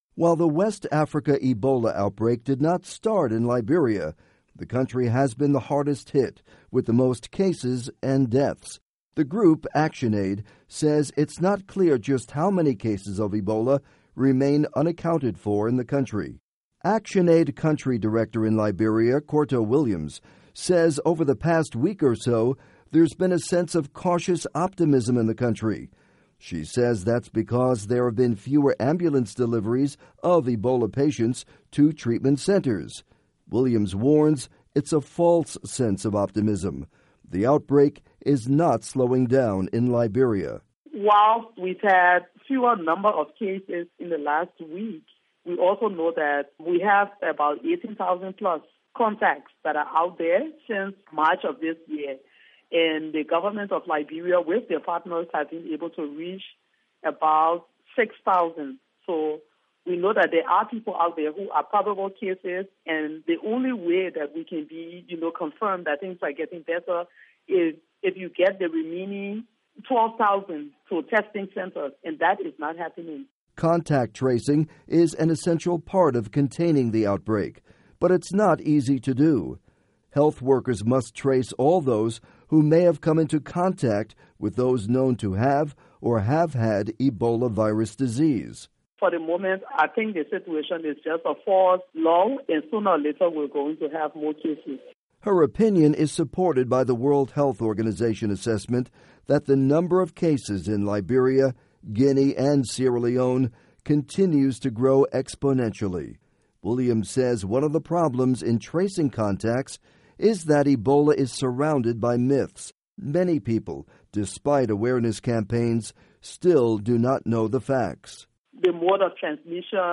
report on ActionAid Ebola efforts in Liberia